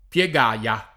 Piegaya [ p L e g#L a ] → Piegaia